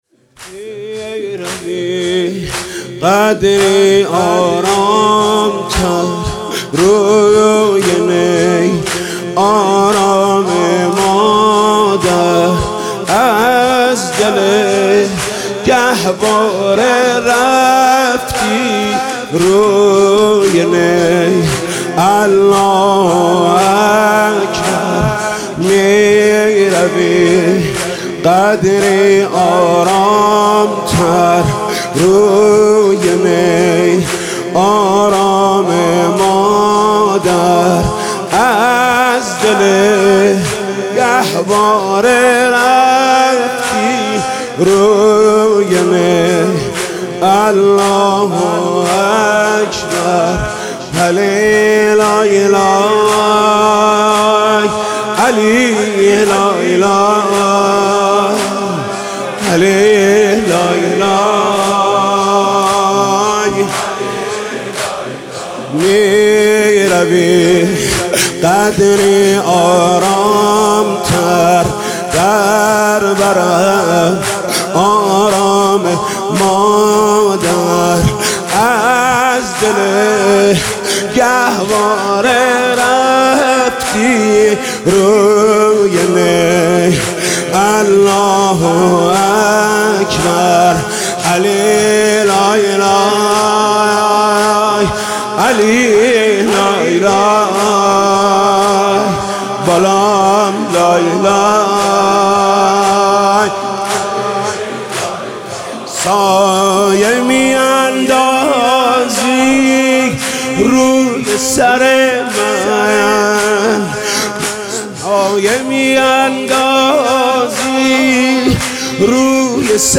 مرثیه‌ سرایی حاج محمود کریمی در شب هفتم محرم الحرام 1439 صوت - تسنیم
صوت مداحی حاج محمود کریمی در شب هفتم محرم الحرام 1439 در هیئت رایه العباس (ع) منتشر می شود.